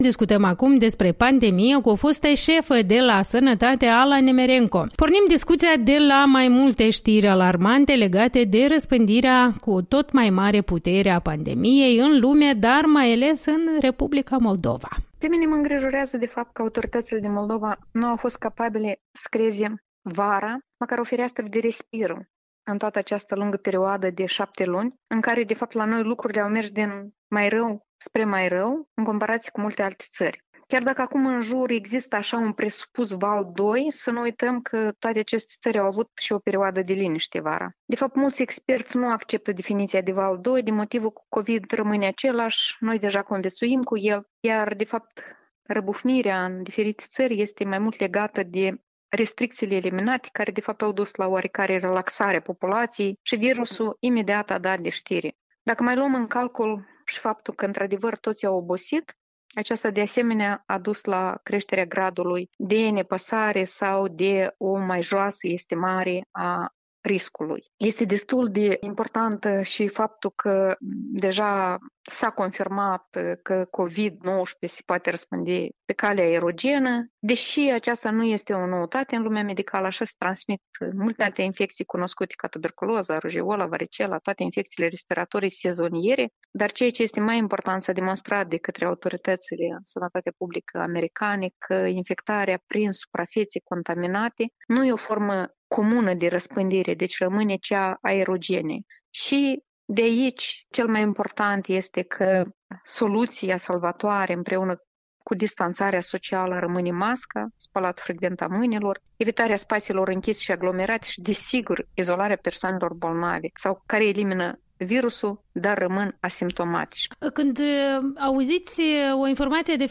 Interviu matinal cu Ala Nemereco, fosta ministră a Sănătății